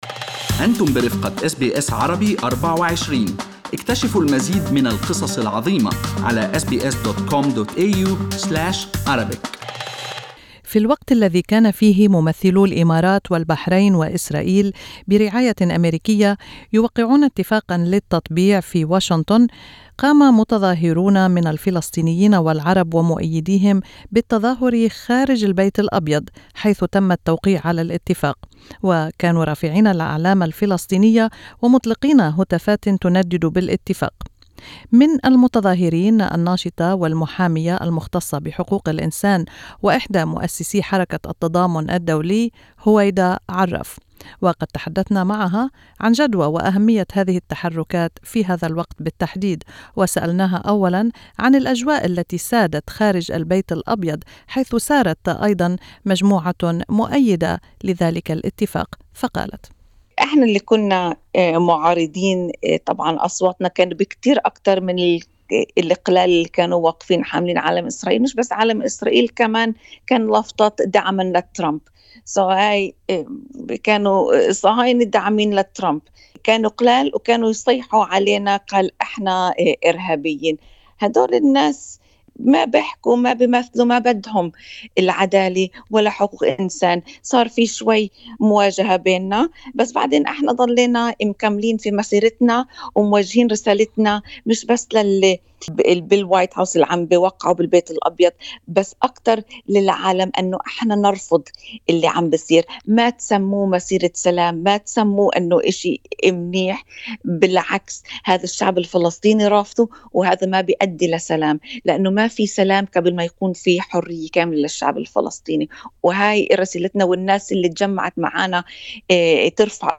استمعوا إلى اللقاء كاملا تحت المدونة الصوتية في أعلى الصفحة.